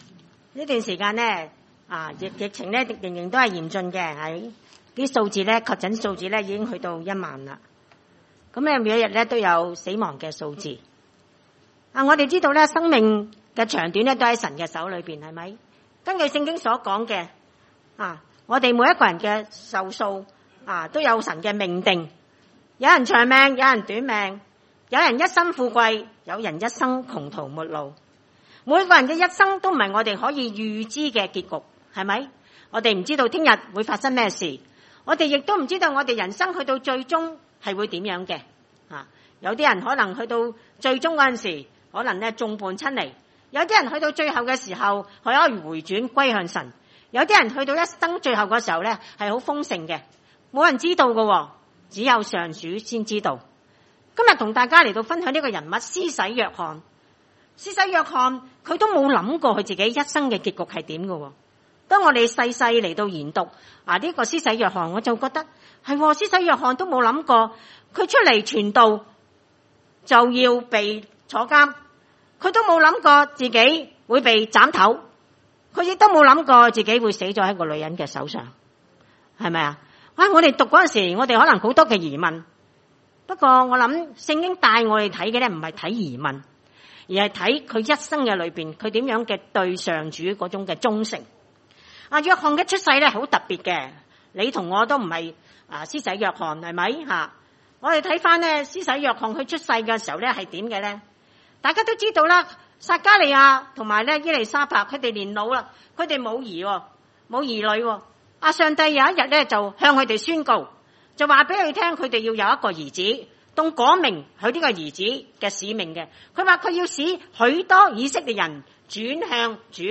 路3：1-20 崇拜類別: 主日午堂崇拜 1.